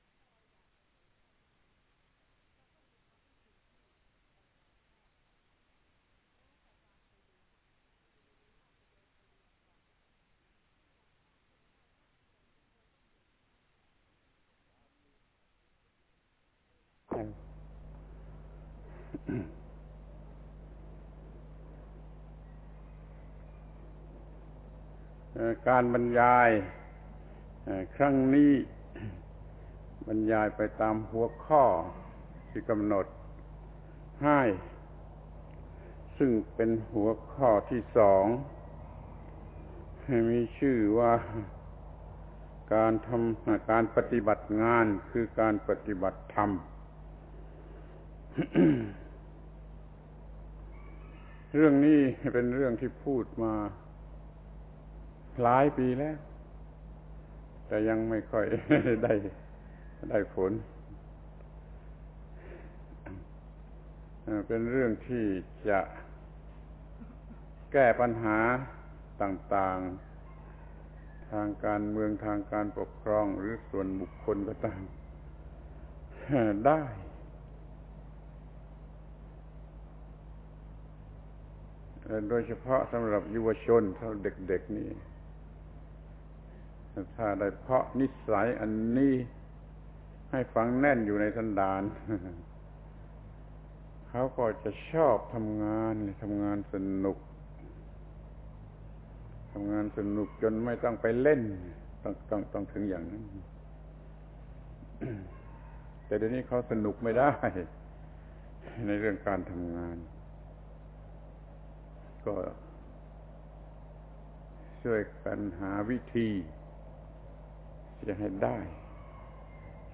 พระธรรมโกศาจารย์ (พุทธทาสภิกขุ) - การประชุมทางวิชาการโครงการพัฒนากิจกรรมการเรียนการสอนจริยศึกษา (สำนักงานการประถมศึกษาแห่งชาติ) ครั้ง 6 การปฏิบัติงานคือการปฏิบัติธรรม